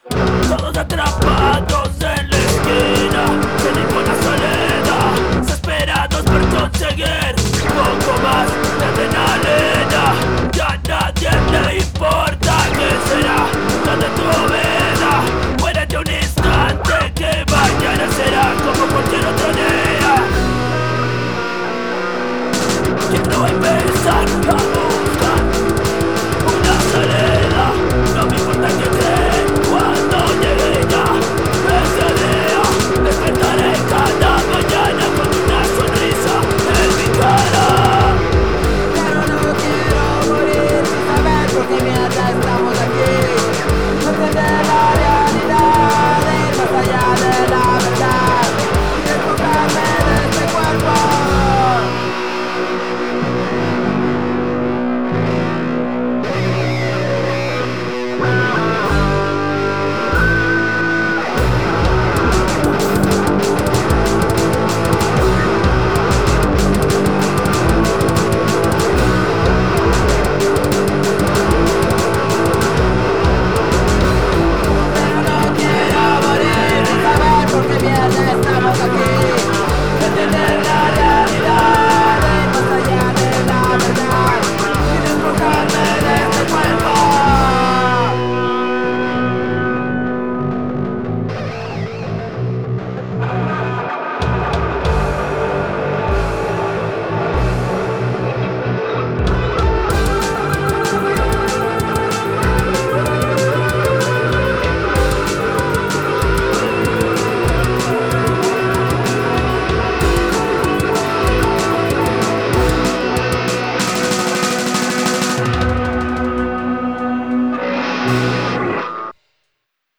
bajo
guitarra, voz
bateria, voz